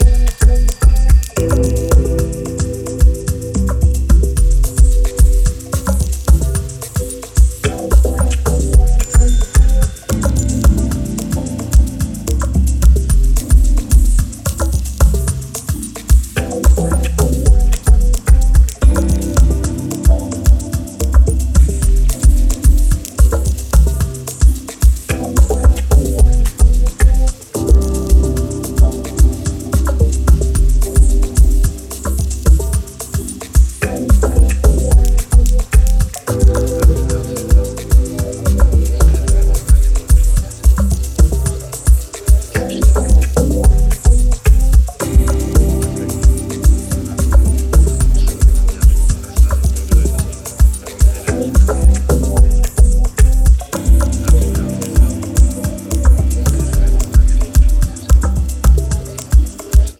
アクアティックに反射するシンセワークやシャッフルしたリズム、微かな話し声等が12分間に渡って朧げに展開する